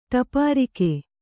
♪ ṭapārike